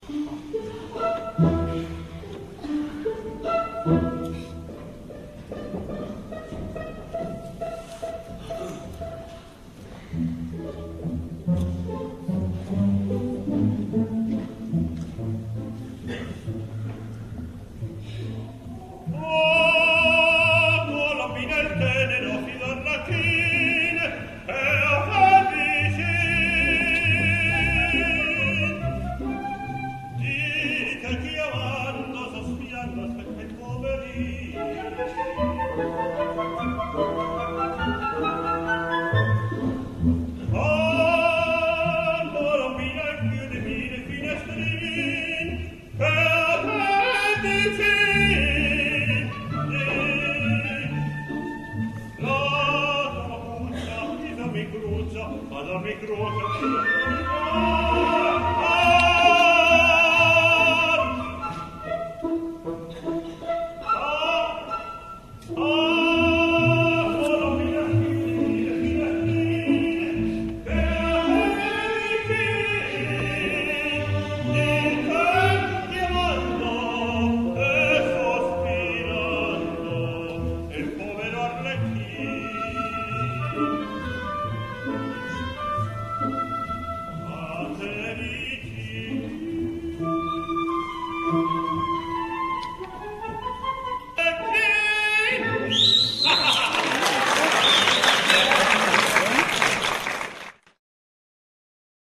After a few years, he got into increasing trouble with his top register.